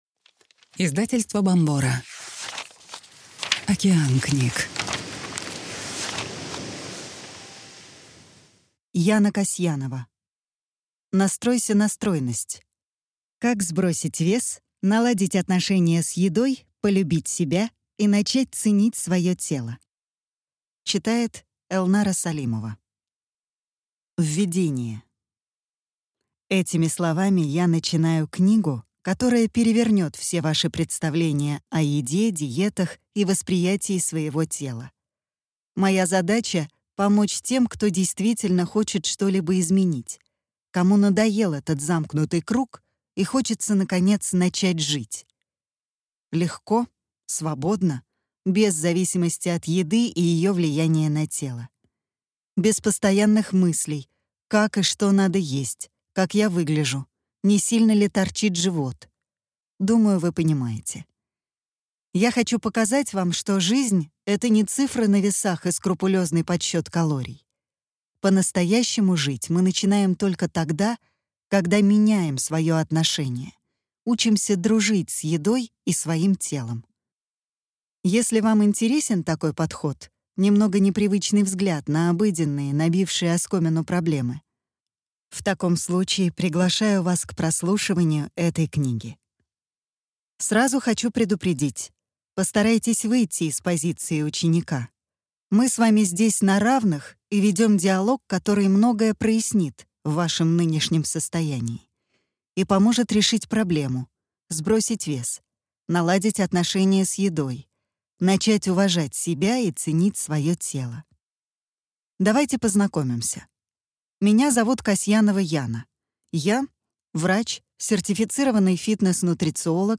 Аудиокнига Настройся на стройность. Как похудеть, наладить отношения с едой и начать ценить свое тело | Библиотека аудиокниг